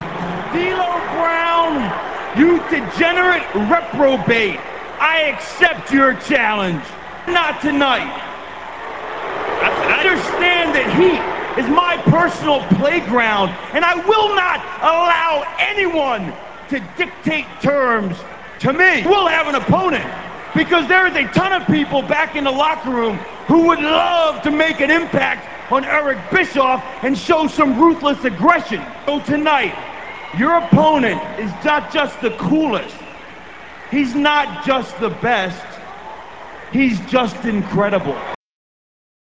- This clip comes from WWE HEAT - [08.18.02]. Raven refuses to fight D-Lo on Heat and sends Justin Credible out instead since Heat is Raven's personal playground.